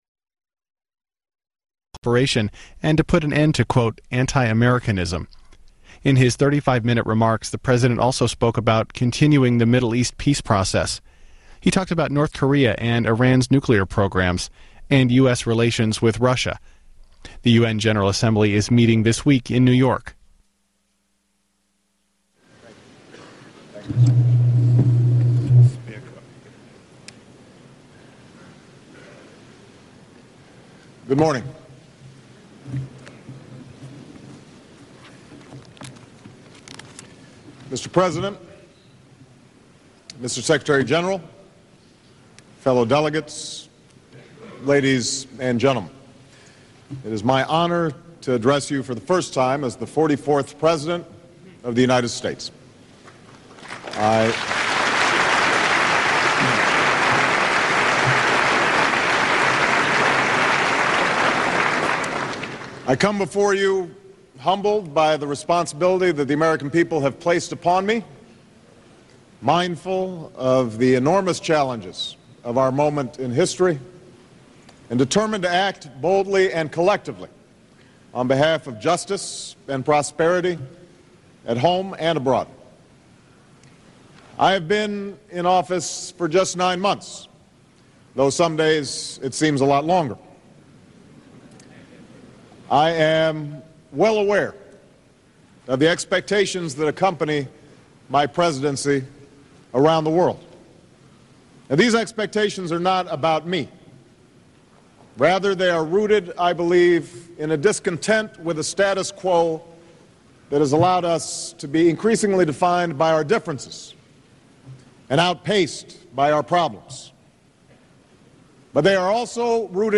U.S. President Barack Obama pledges a new era of engagement at the United Nations and urges international cooperation on several global isuses in his first address to the U.N. General Assembly
Broadcast on C-SPAN, Sept. 23, 2009.